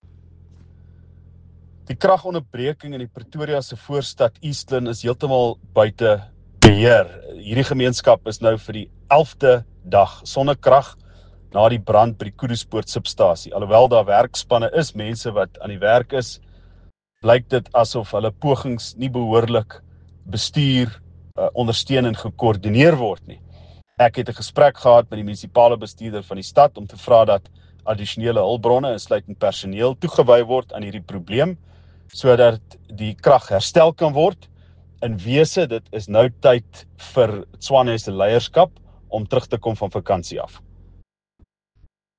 Afrikaans soundbites by Ald Cilliers Brink